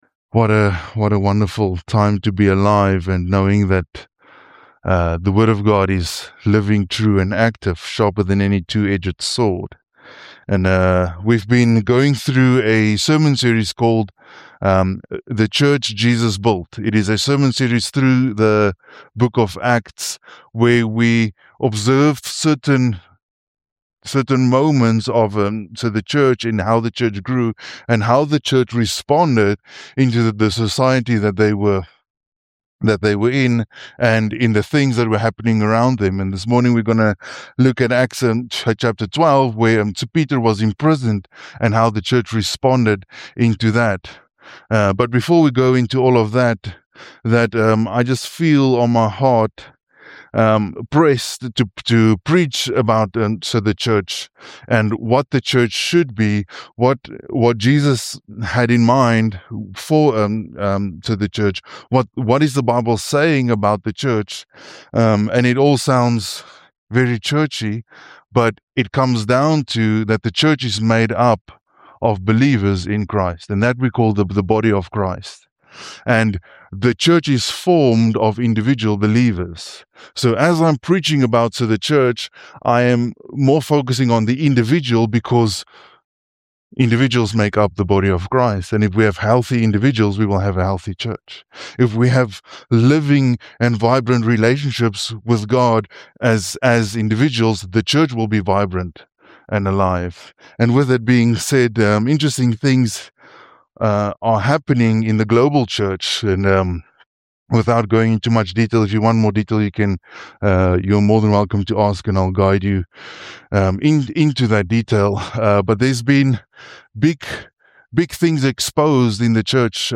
GBC Podcast to share audio sermons and talks.